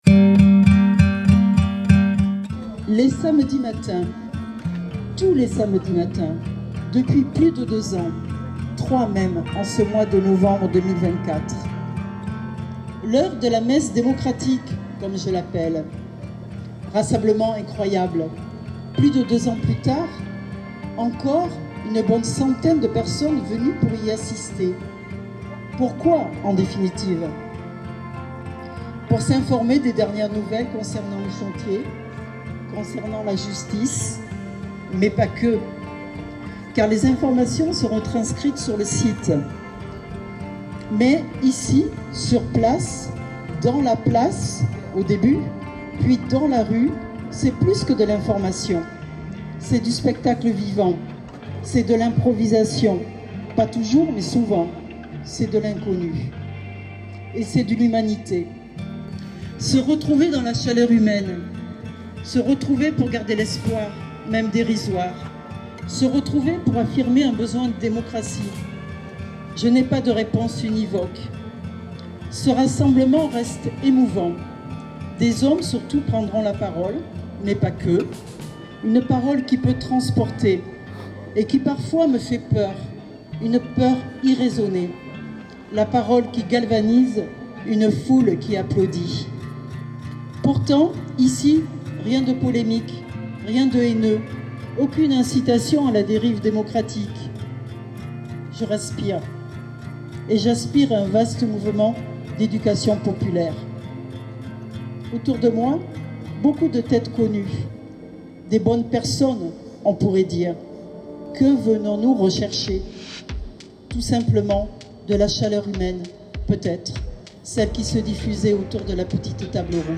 Réunion hebdomadaire du collectif Bancs Publics, samedi 7 décembre : l’indignation des opposant·es est croissante comme leur détermination pour se battre contre des travaux « ruineux » et  « catastrophiques », avec des « dépassements budgétaires colossaux » et des « dégâts humains et environnementaux irréversibles.
Lecture